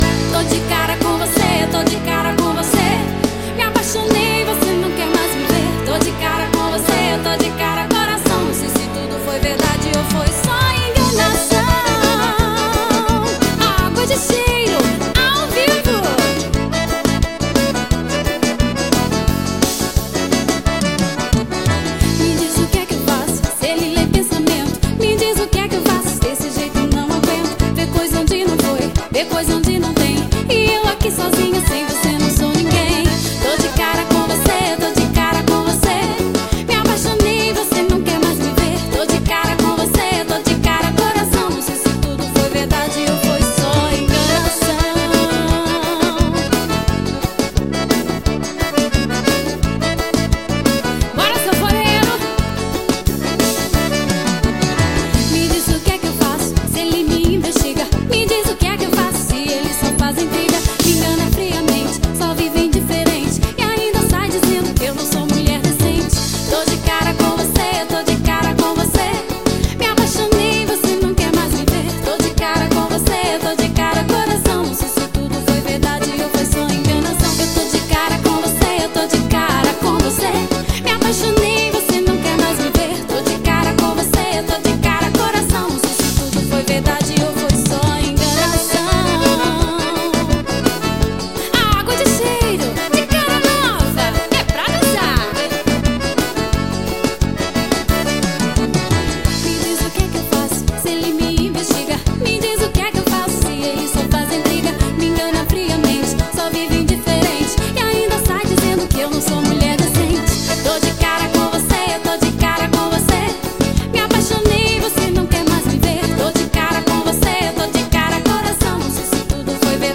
GRAVACAO NO LG STUDIO JOAO PESSOA PB.